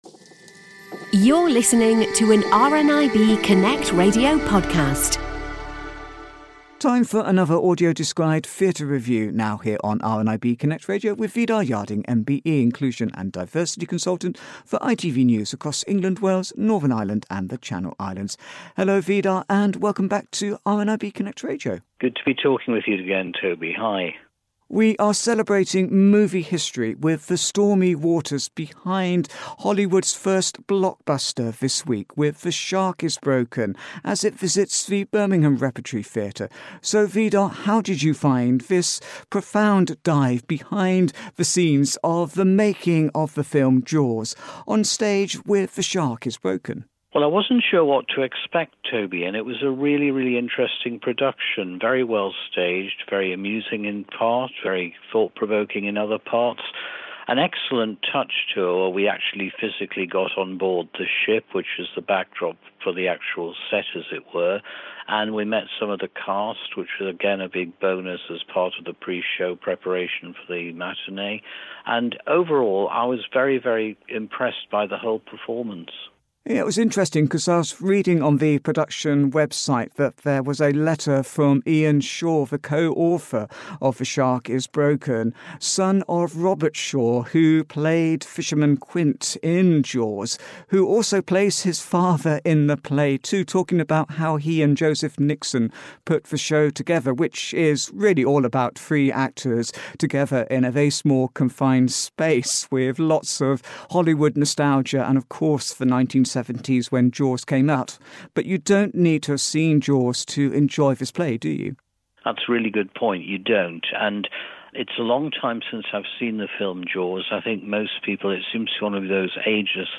AD Theatre Review